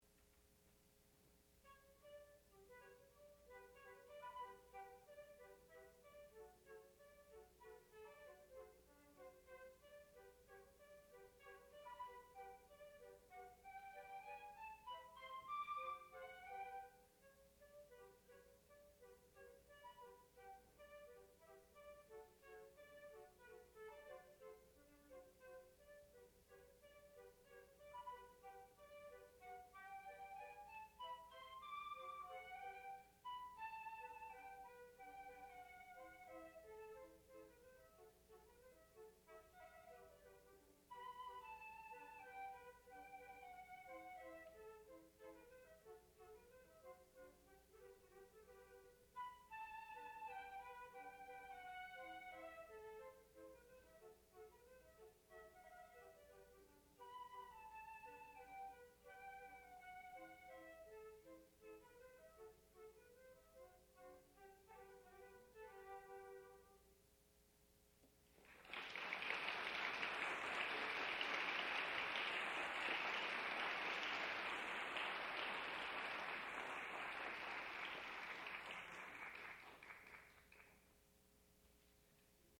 sound recording-musical
classical music
flute